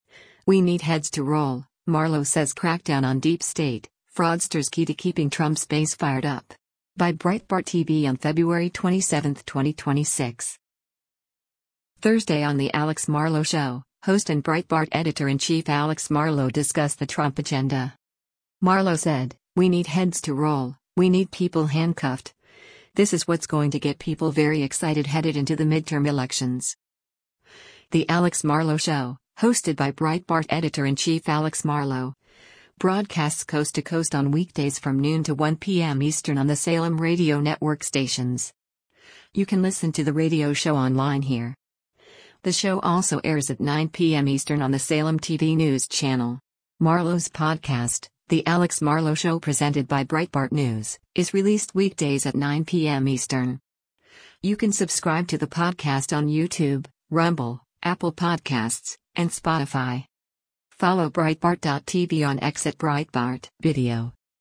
Thursday on “The Alex Marlow Show,” host and Breitbart Editor-in-Chief Alex Marlow discussed the Trump agenda.